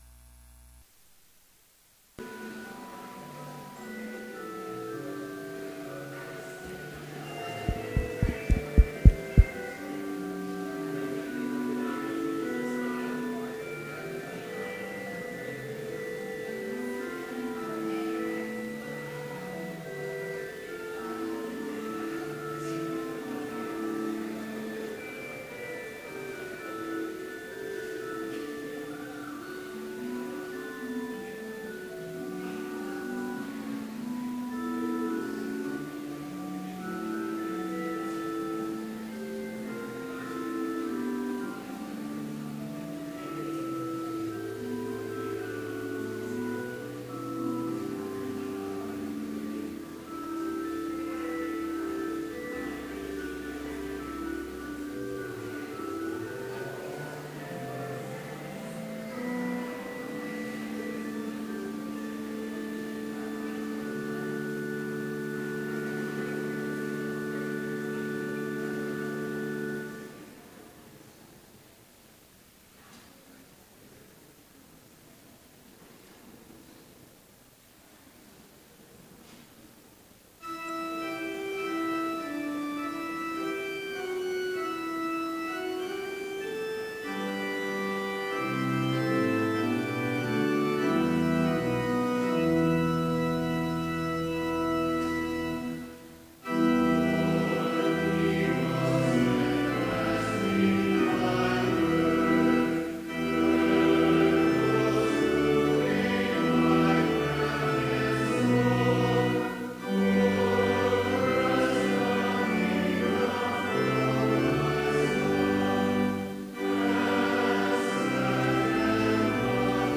Complete service audio for Chapel - October 28, 2015